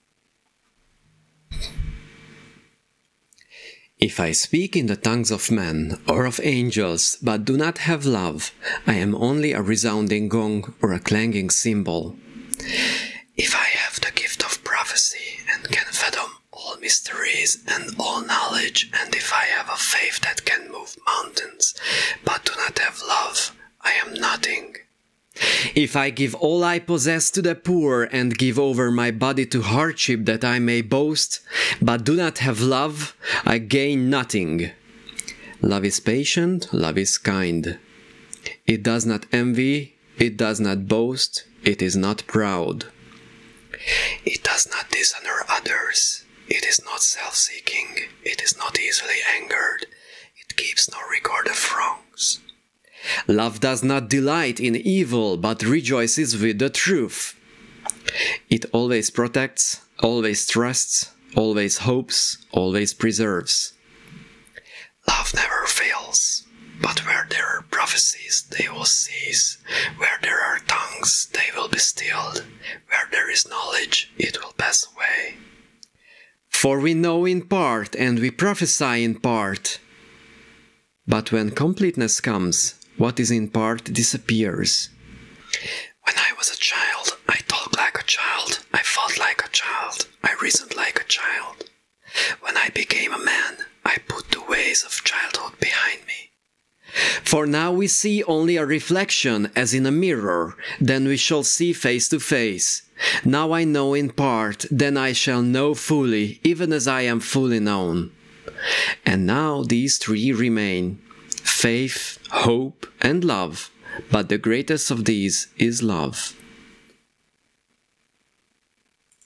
Compression
This is why our example audio consists of different speaking strengths, so that we could see the dramatic effect of this filter.
Based on the image below, I’ve decided to cut at this point, as this is above most of the whispering, which cuts hard pops and “s”-es even in the “whisper zone”.
Basically, this is a very strong compression ratio, it is almost a limiter.